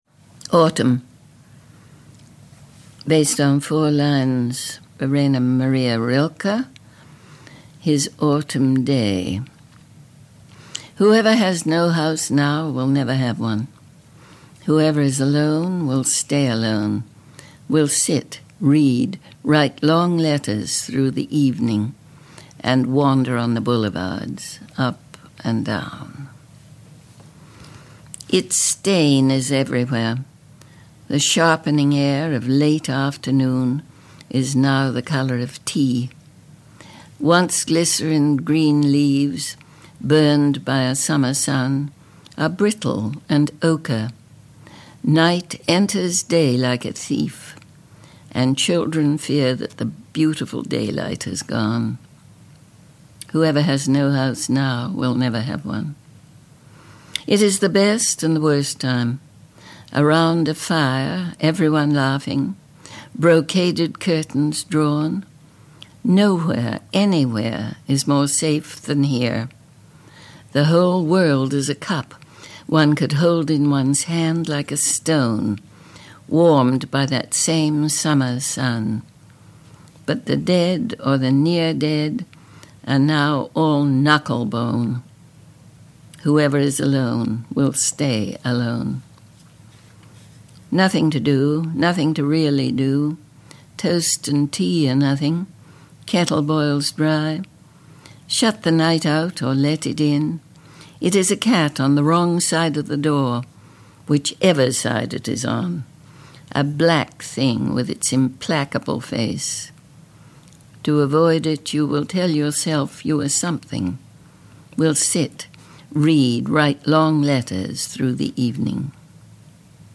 P.K. Page reads Autumn from Hologram: A Book of Glosas
This poem is from The Filled Pen: an Outlaw Editions Audio book. Poems by P.K. Page Written and narrated by P.K. Page.